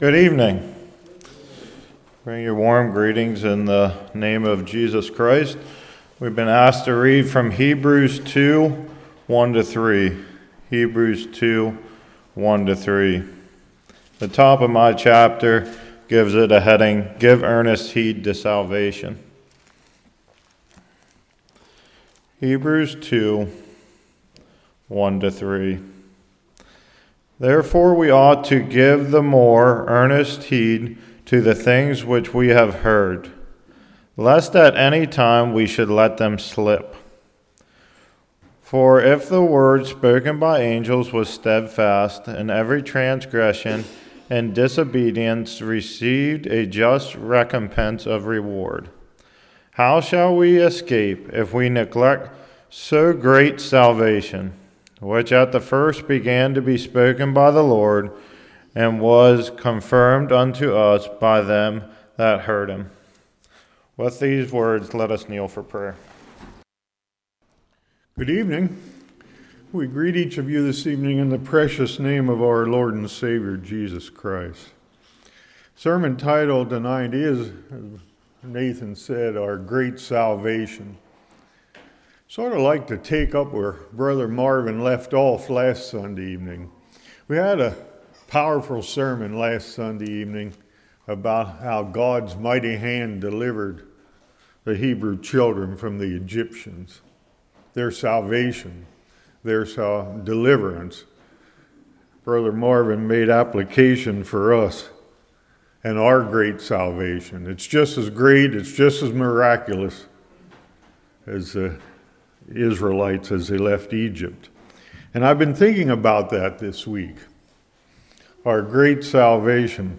October 5, 2025 Our Great Salvation Passage: Hebrews 2:1-3 Service Type: Evening Do you value your salvation?